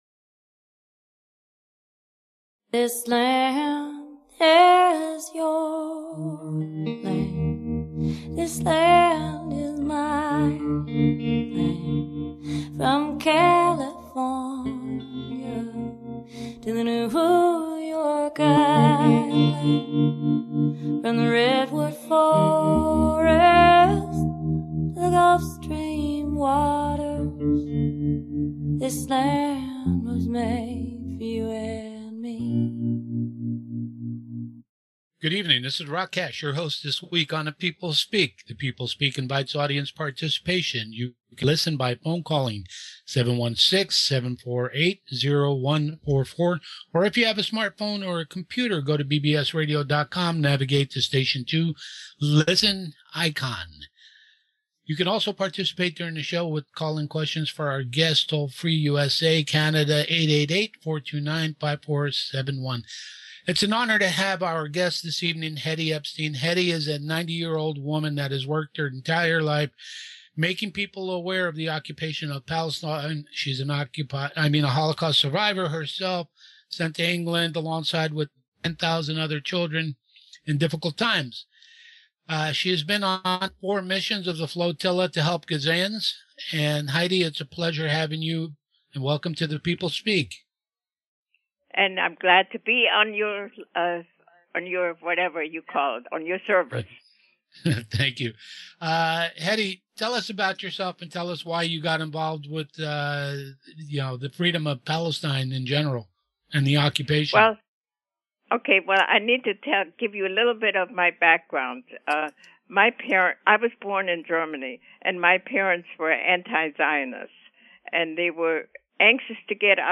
Talk Show Episode
The People Speak has evolved over the years with many great guests who have been interviewed by some very fine hosts.